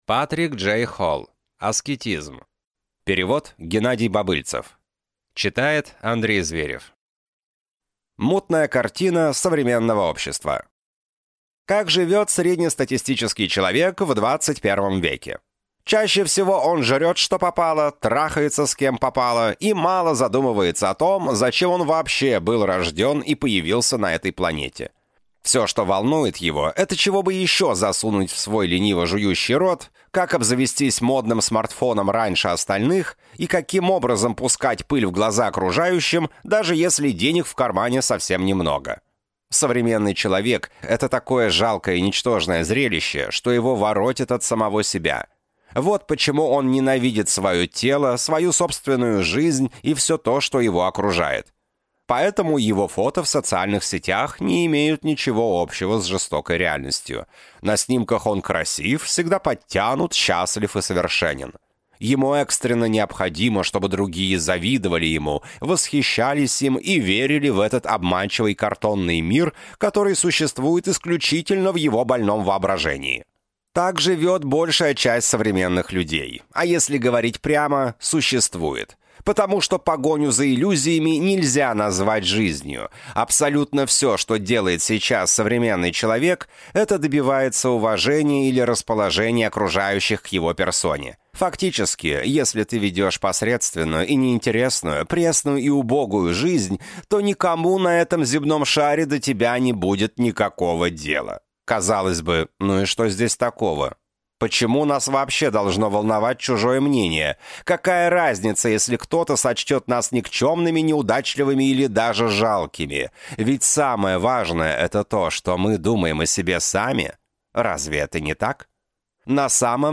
Аудиокнига Аскетизм | Библиотека аудиокниг